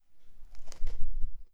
snow step.wav